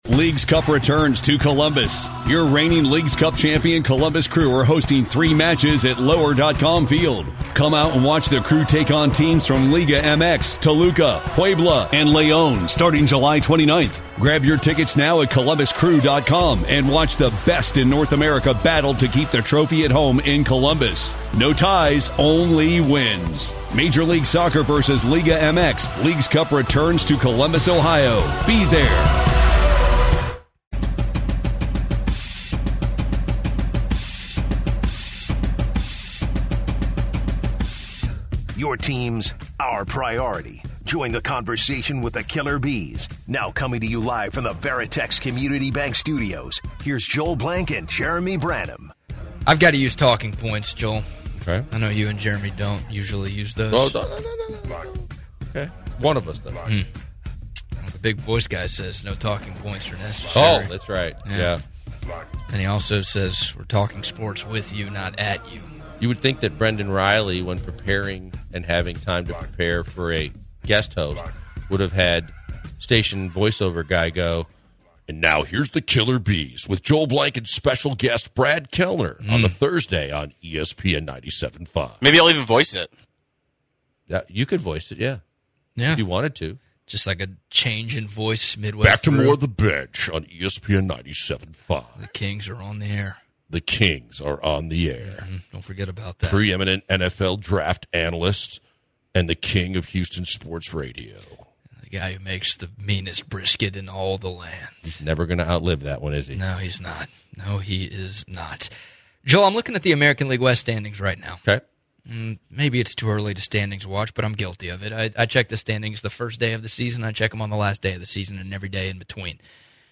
Our hosts start off the hour with some Major League Baseball talk, standings, teams, players, & more. Focusing on the Astros, our hosts have a conversation over their future fixtures and their overall thoughts for the games to come for the Houston team. Moving onto the second half of the hour, our Killer B's host talk about the National Football Team; divisional rankings, transfers and trades, takes, & more.